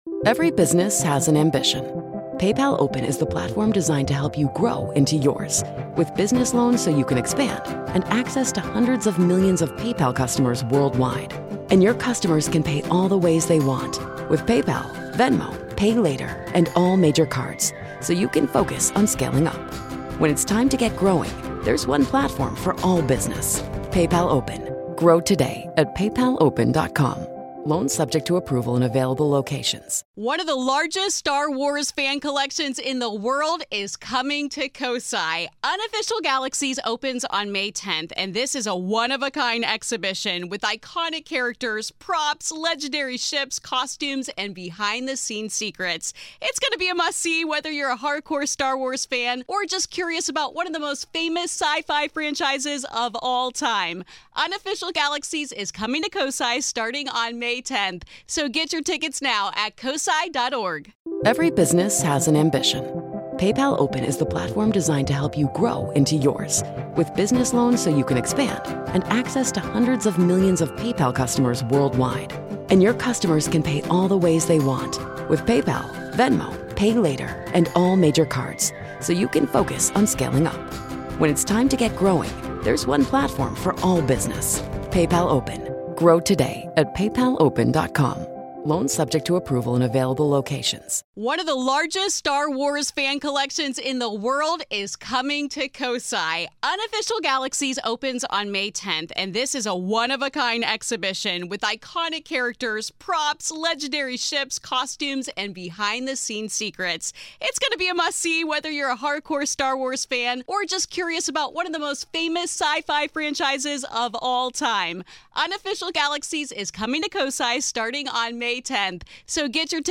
a conversation with medium and author